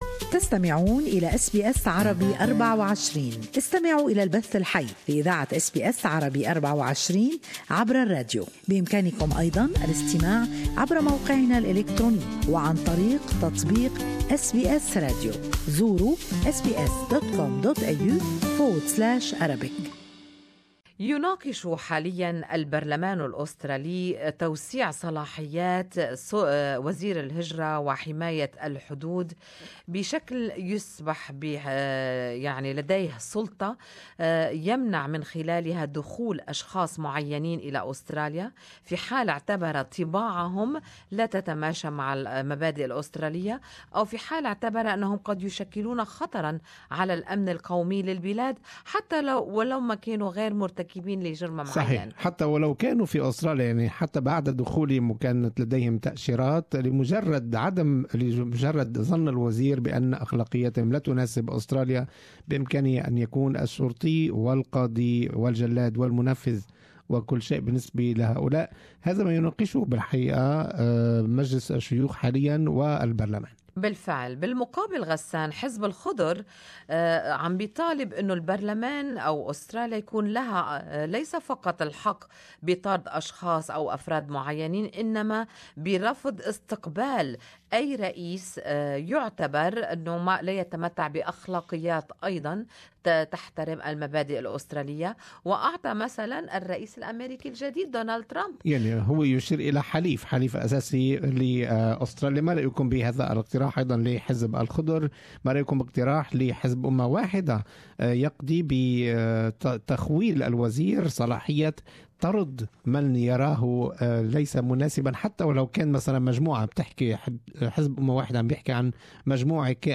A proposal that gives the Immigration Minister more power and control in determining who can come into Australia is being discussed by the parliament. Good Morning Australia discussed this topic with its listeners during the live talk back segment.